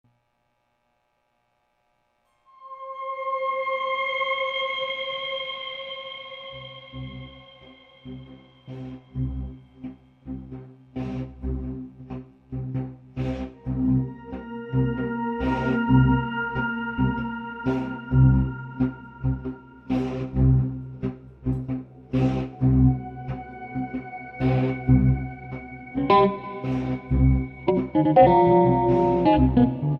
Acoustic, Electric Guitar and SuperCollider
Acoustic, Electric Guitar and Percussion
solo is processed through a ring modulator.